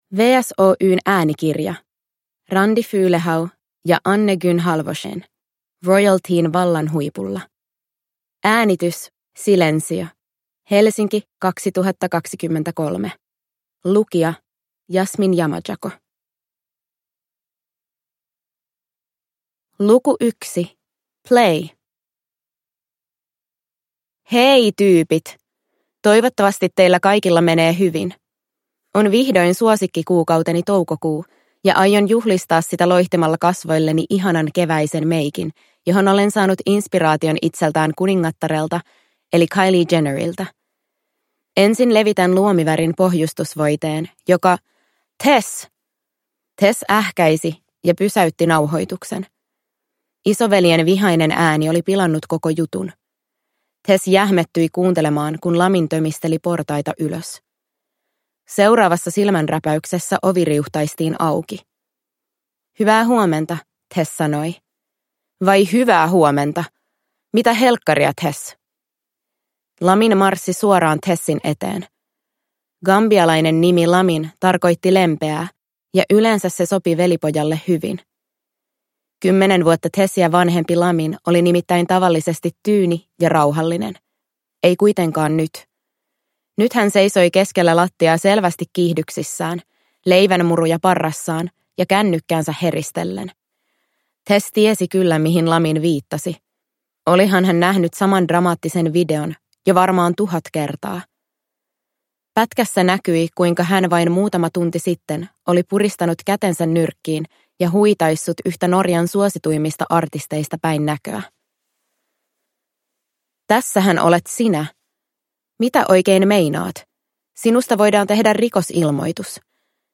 Royalteen 3: Vallan huipulla – Ljudbok